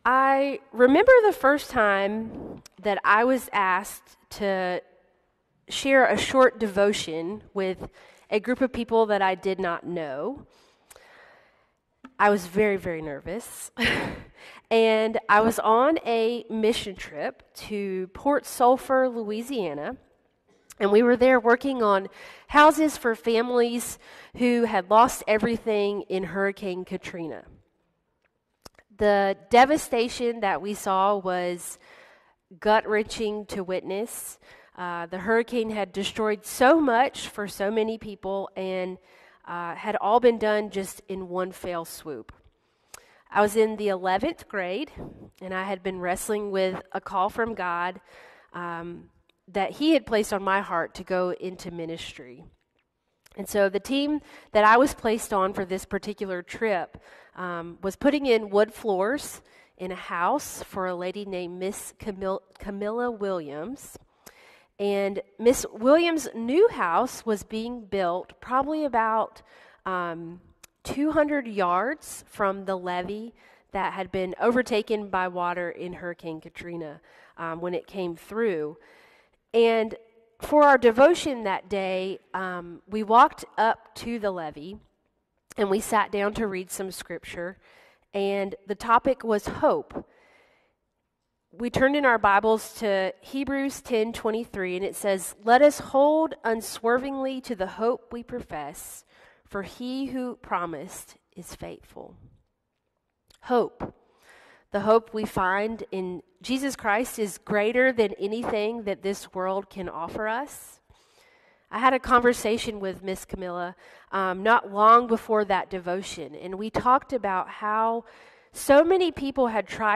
Sermons | Forest Hills Baptist Church